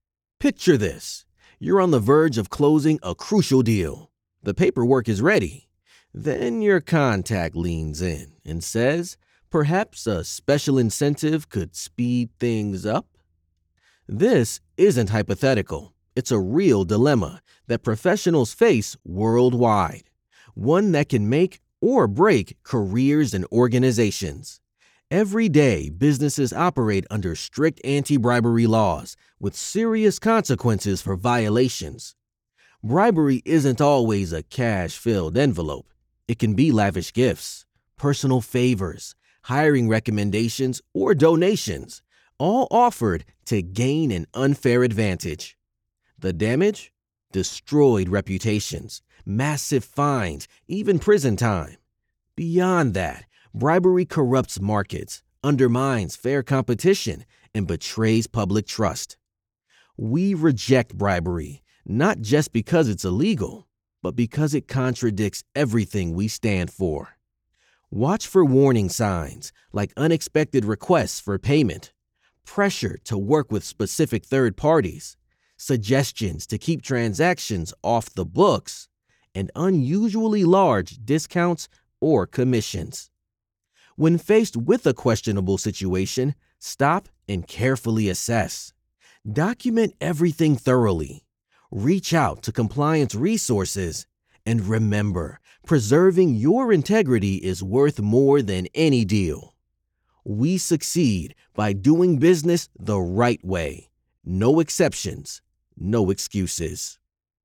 Commerciale, Urbaine, Cool, Amicale, Chaude, Naturelle
Corporate
If you’re looking for a grounded, believable male voice for your project, look no further!